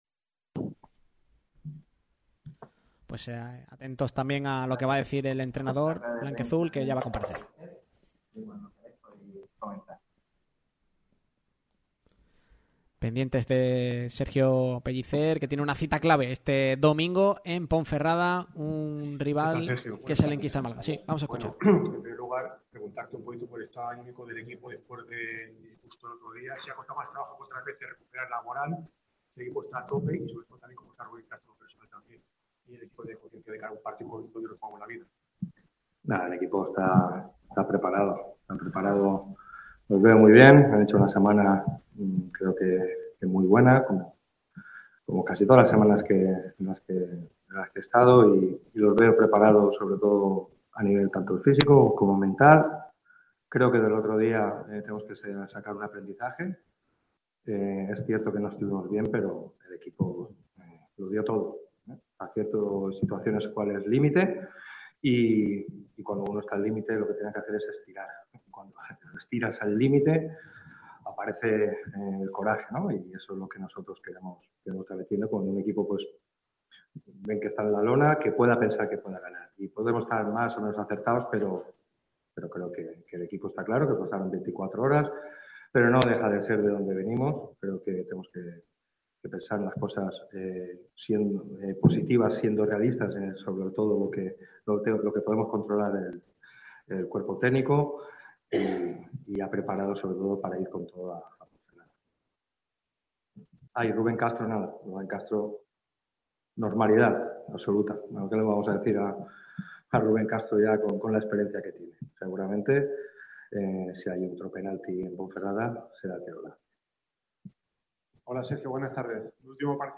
El técnico del Málaga CF ha comparecido este mediodía en la sala de prensa del Estadio de La Rosaleda en la previa del vital choque del domingo ante la Ponferradina (16:15 horas), rival directo por la permanencia.